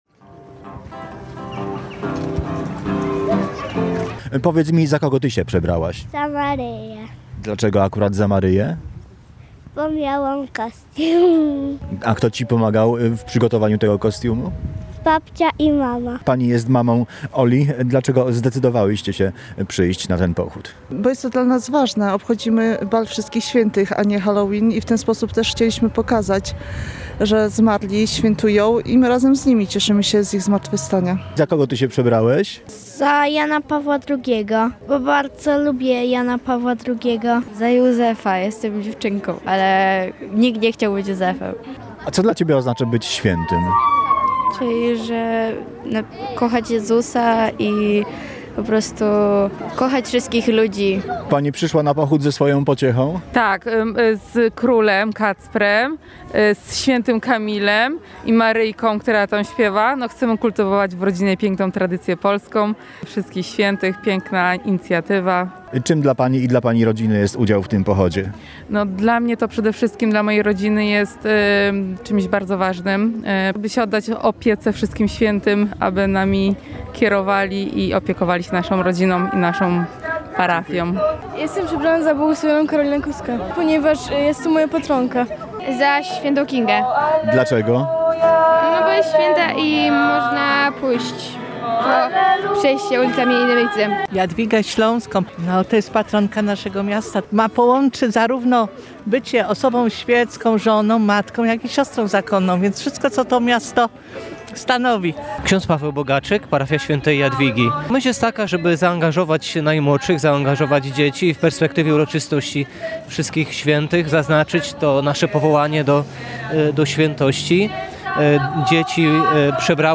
Pochod-Wszystkich-Swietych-w-Debicy-1.mp3